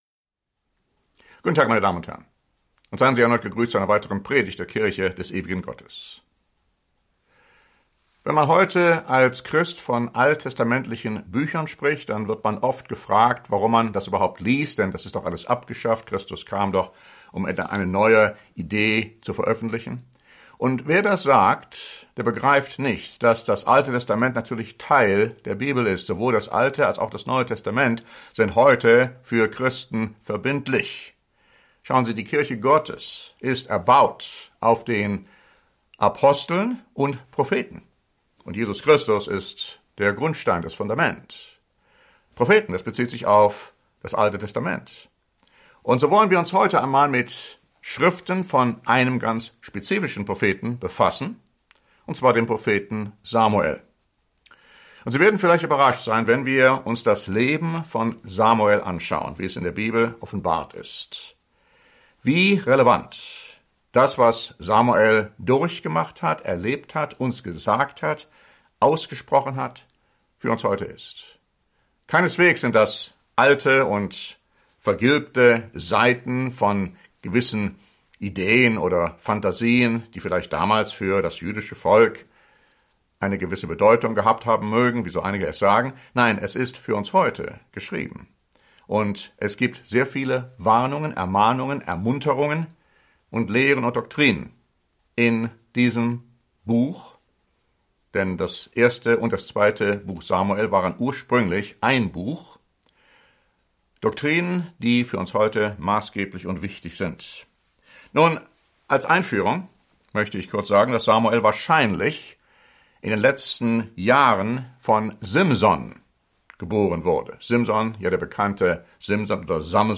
Die Bibel gibt uns wichtige Erkenntnisse aus dem Leben des gerechten Propheten, Sehers, Richters und Priesters Samuel, die gerade heute für uns sehr relevant sind. Im ersten Teil dieser neuen Predigtserie geben wir biblische Hintergrundinformation und warnen vor der Weigerung, Gott zu gehorchen; und wir behandeln die ersten drei Kapitel des 1. Buches Samuel, die uns von der wunderbaren Empfängnis und Geburt Samuels berichten, seiner lebenslangen Bestimmung als ein Gottgeweihter; und seinen Jugendjahren. Wir lesen auch von den beiden ruchlosen Söhnen des Hohepriesters Eli und Gottes Entscheidung, sie an einem Tag zu töten.